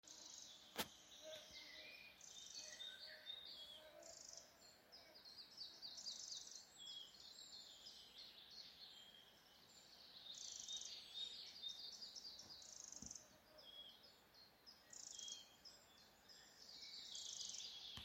Putni -> Mušķērāji ->
Mazais mušķērājs, Ficedula parva
StatussUztraukuma uzvedība vai saucieni (U)